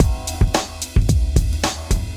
110LOOP B8-L.wav